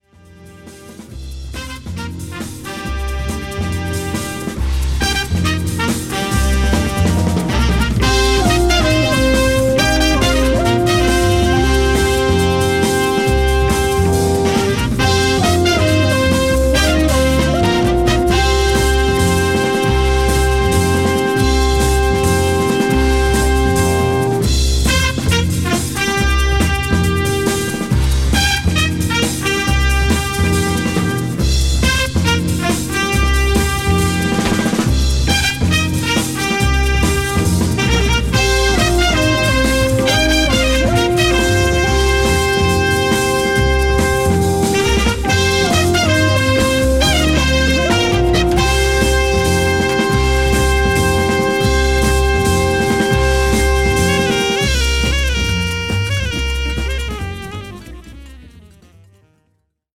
A：Original Mix